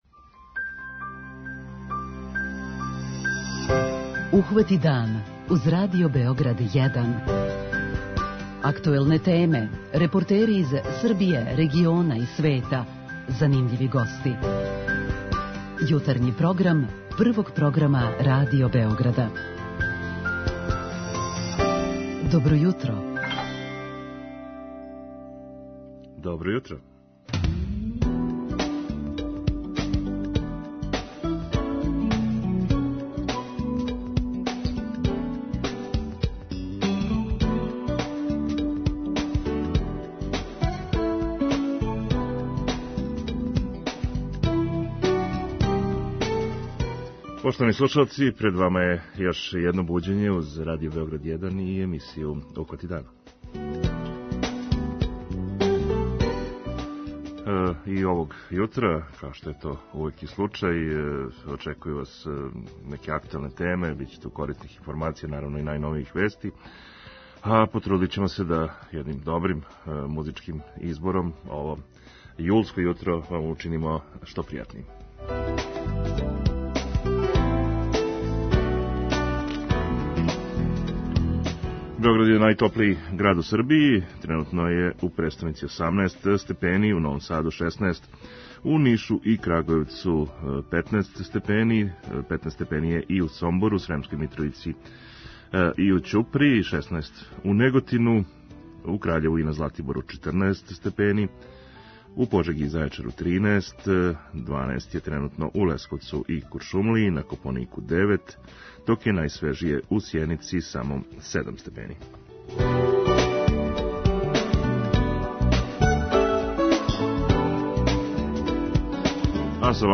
У нашој емисији чућете како су церемоније свечаног отварања, као најгламурознији догадјај који сваки организатор доживљава као ствар престижа, обележиле олимпијске игре кроз историју. Уживо ће нам се у програм укључити и наши репортери из Токија